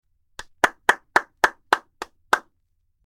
Sound Effect: Clapping slow - The AI Voice Generator
Listen to the AI generated sound effect for the prompt: "Clapping slow".